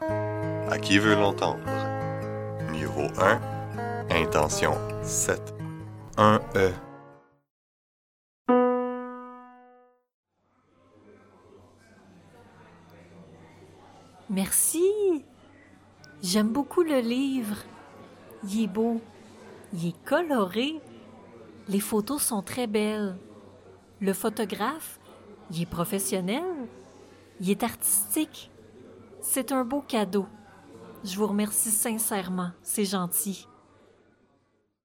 Indicatif présent : Associer [je] à il est o